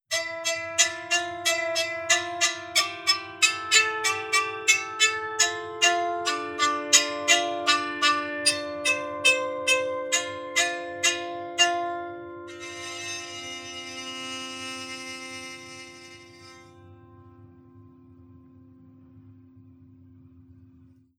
• soprano diatonic psaltery impro.wav
soprano_diatonic_psaltery_impro_fc8.wav